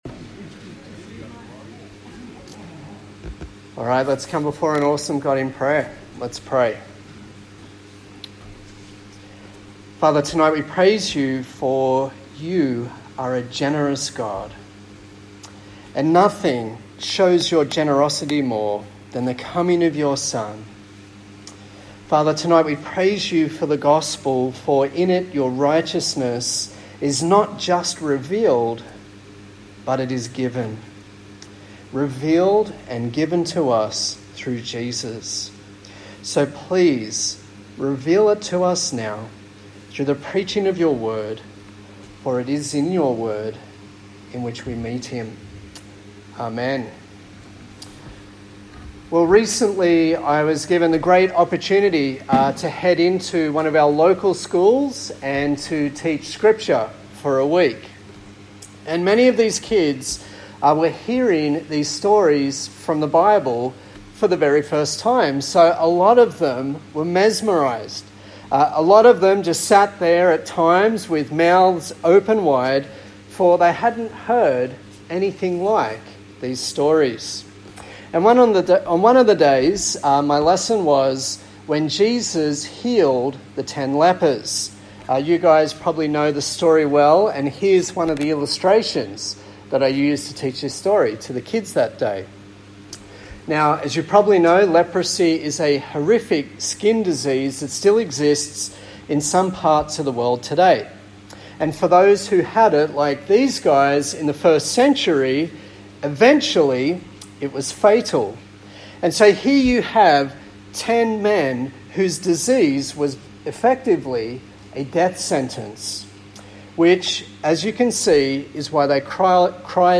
A sermon in the series on the book of Galatians